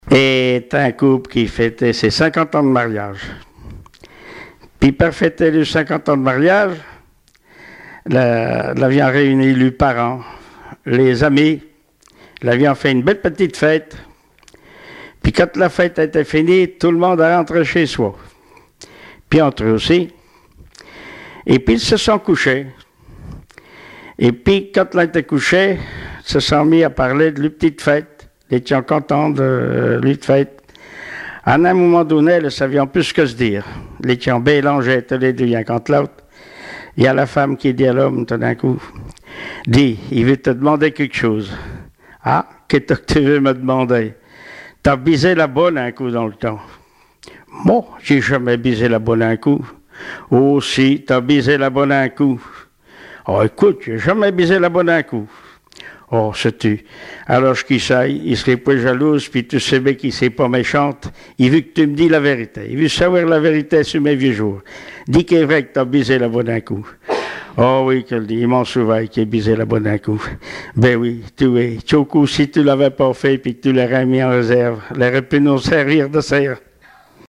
Genre sketch
Témoignages et chansons traditionnelles et populaires
Catégorie Récit